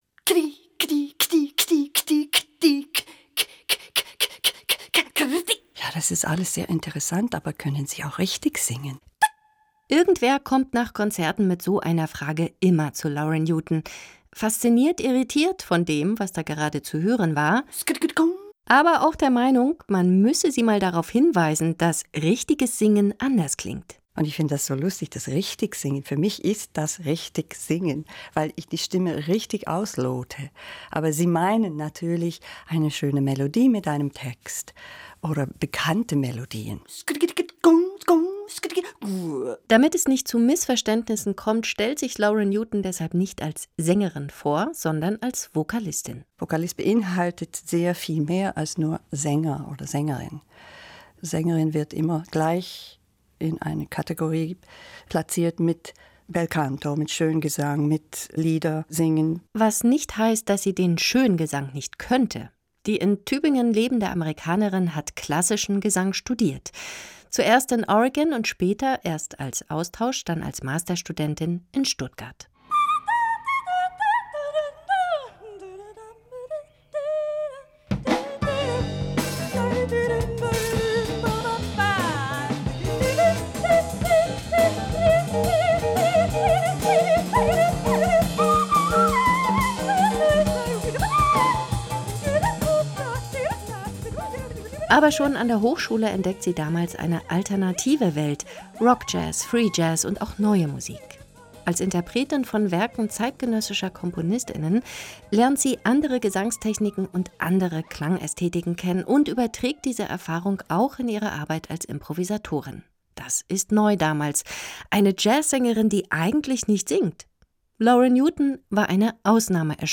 Porträt.